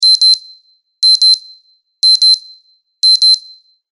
buzzer.mp3